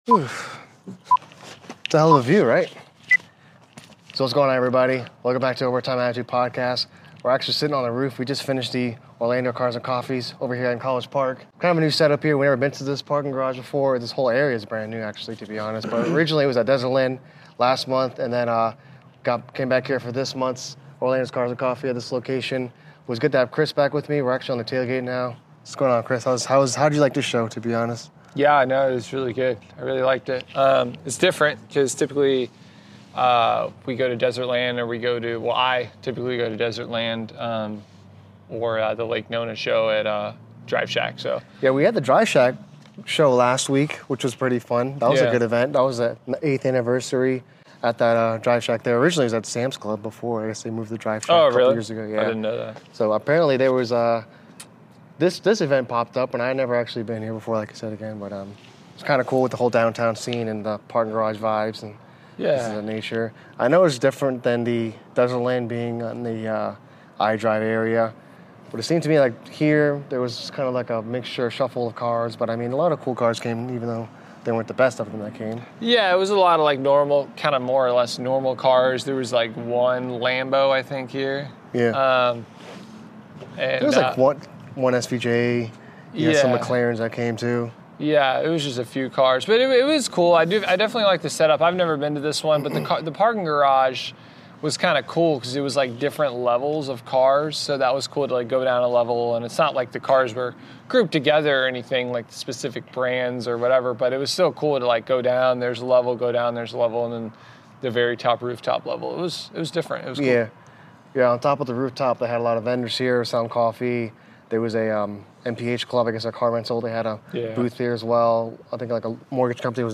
We made big changes to our studio with new cameras, background, and new table.
With 3 cameras, the soundboard, and audio mixer we are one step closer to going full time. We talked about Rental Properties, Stock Market, Exotic Candy, and much more.